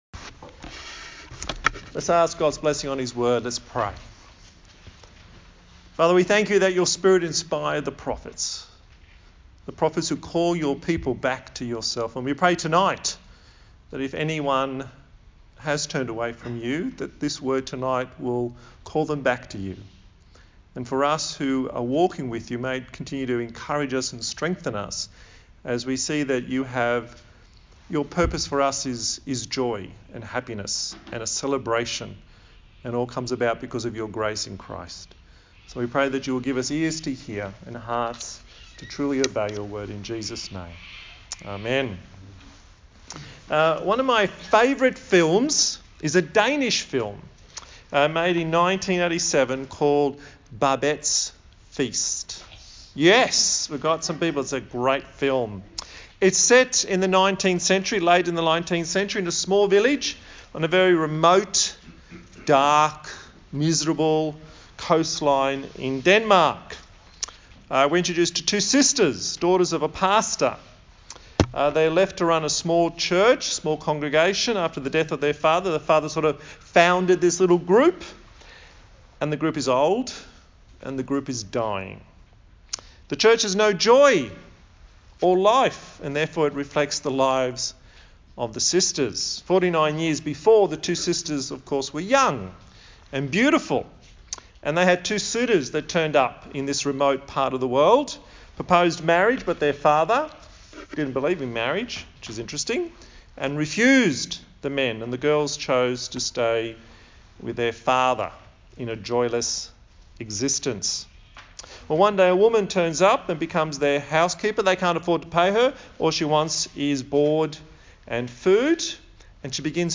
A sermon in the series on the book of Zechariah